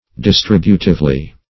Distributively \Dis*trib"u*tive*ly\, adv.